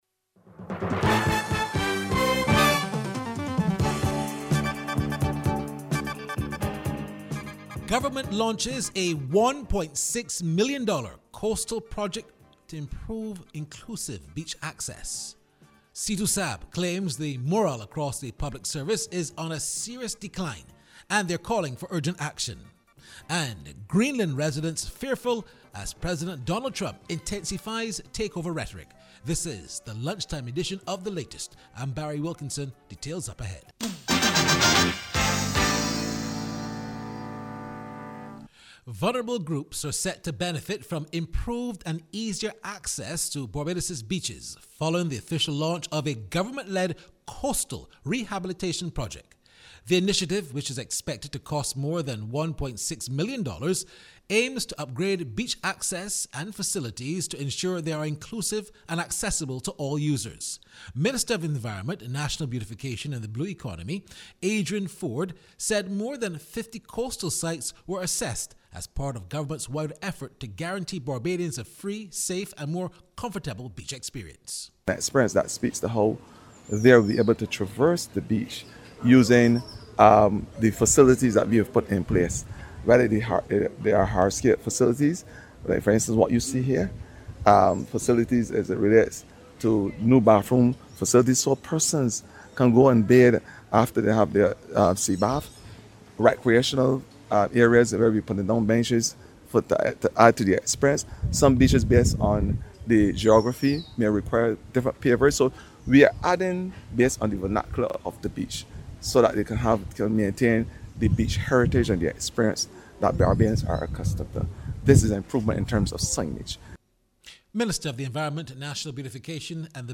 His comments came this morning during a special sitting of the Supreme Court to mark the ascension of the new Chief Justice, Leslie Haynes, to the office.